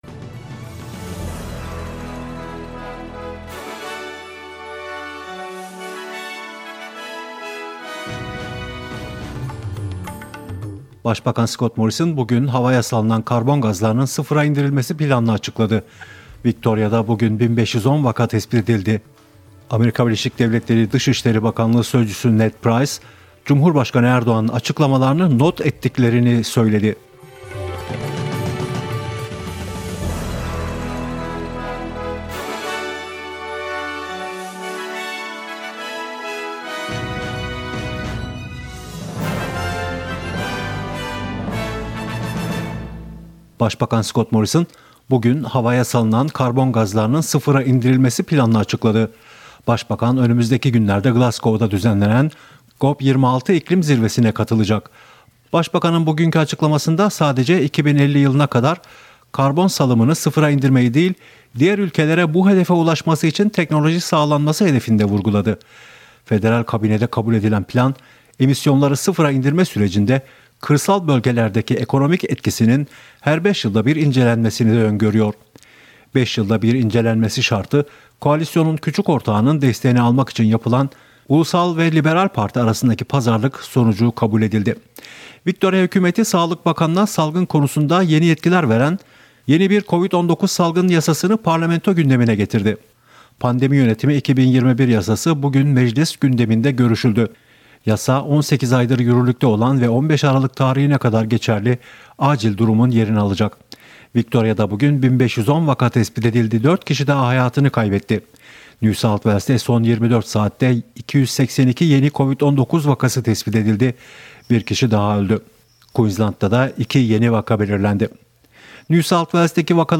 SBS Türkçe'den Avustralya, Türkiye ve dünya haberleri.